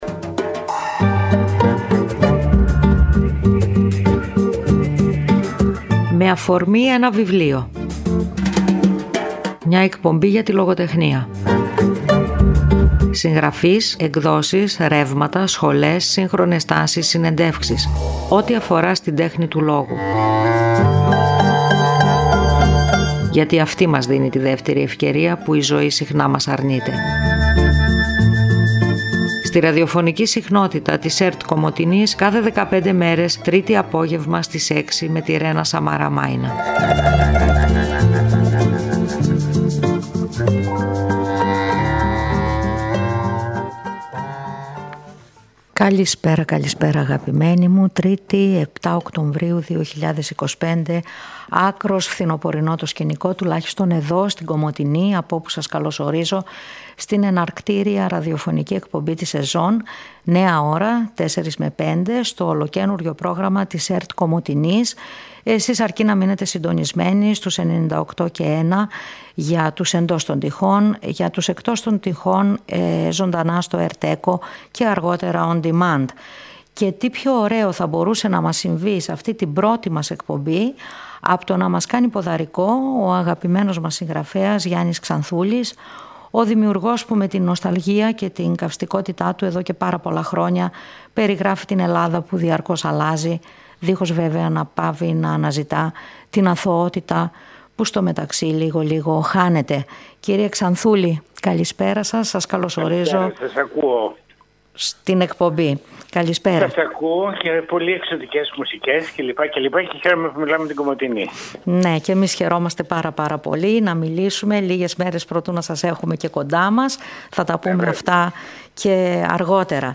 Πριν Αλώσουν την Αθήνα κάνουν μια ραδιοφωνική στάση στην Κομοτηνή.